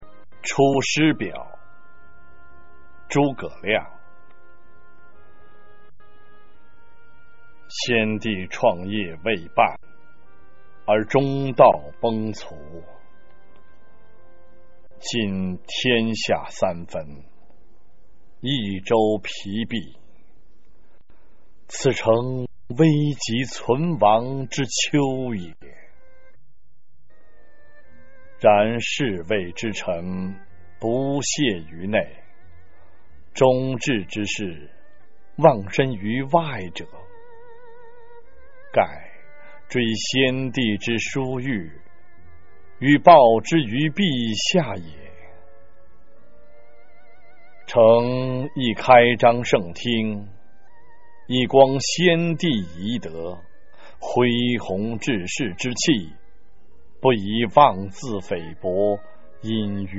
九年级语文下册 23《出师表》男声配乐朗读（音频素材）